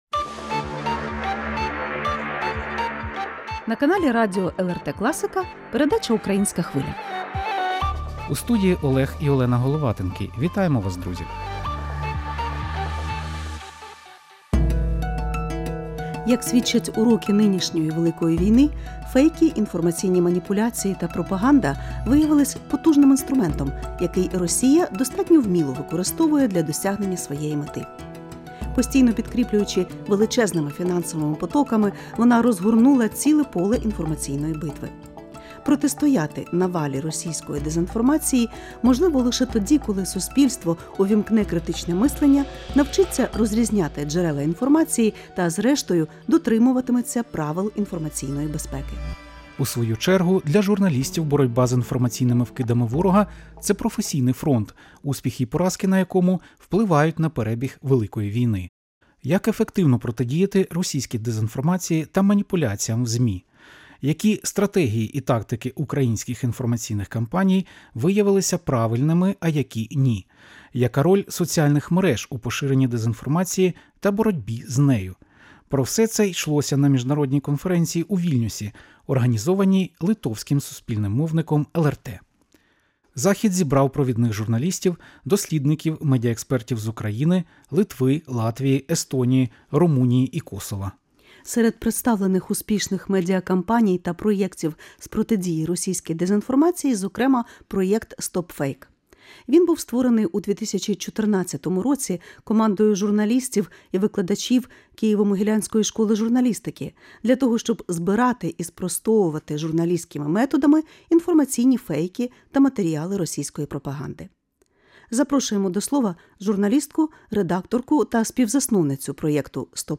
в інтерв'ю з журналісткою